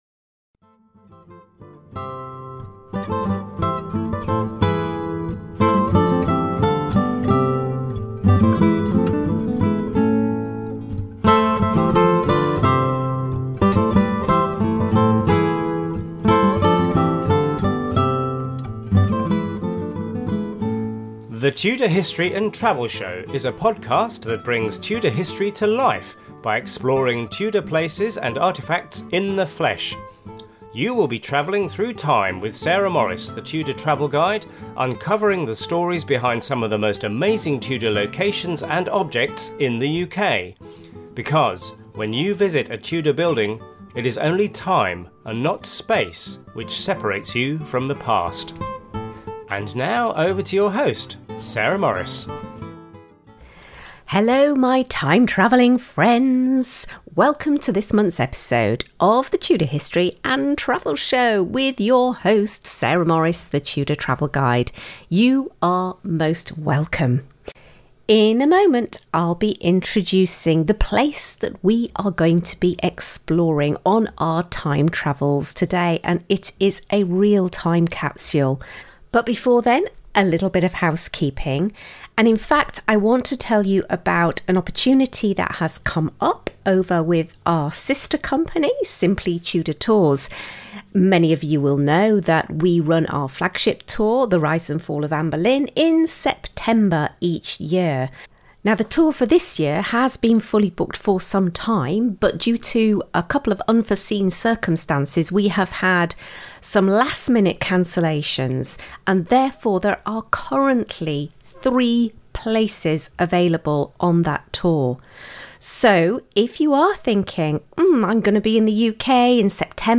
Note : This is a show notes page accompanying my on-location podcast, recorded in spring 2025. An Introduction to Haddon Hall Haddon Hall, located in Derbyshire’s beautiful Peak District, is one of England’s most remarkable and best-preserved medieval manor houses.